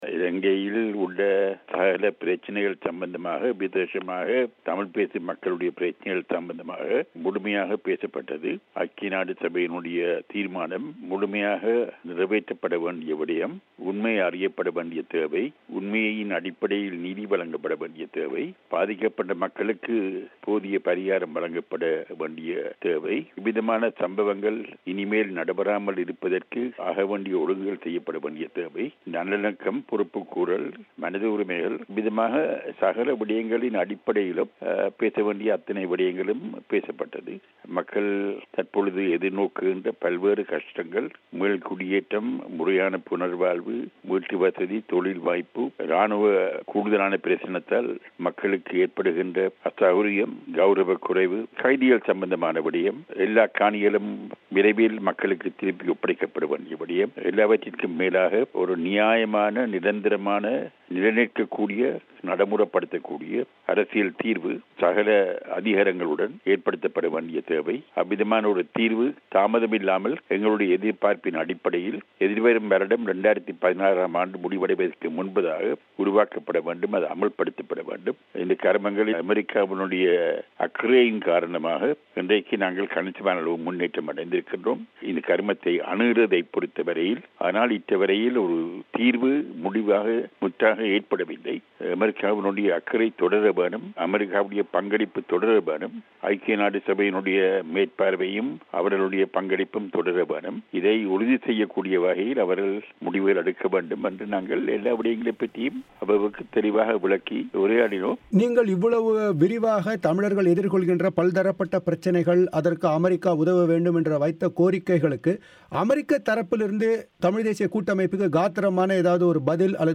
இலங்கையிலுள்ள தமிழர்களுக்கு அமெரிக்கா நூறு சதவீதம் ஆதரவு அளிக்கும் என அமெரிக்க உயரதிகாரி சமந்தா பவர் உறுதியளித்தார் என சம்பந்தர் பிபிசி தமிழோசையிடம் தெரிவித்தார்.